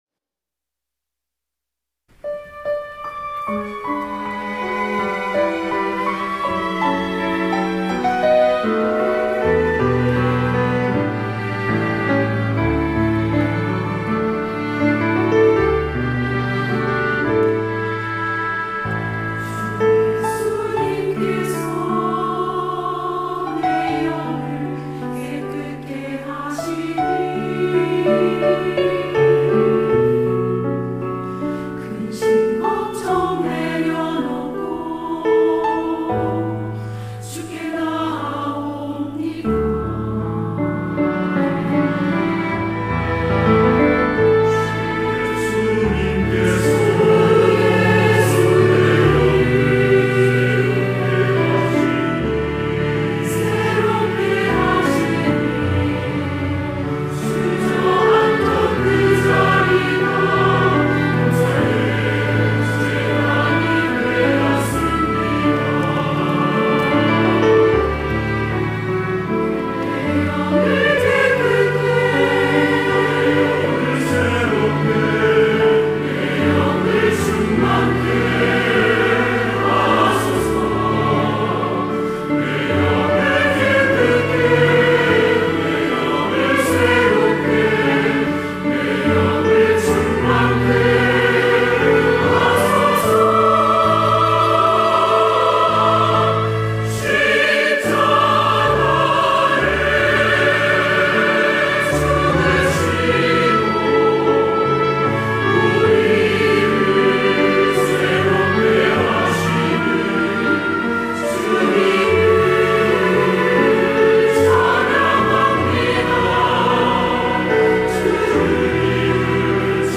할렐루야(주일2부) - 주께서 내 영을
찬양대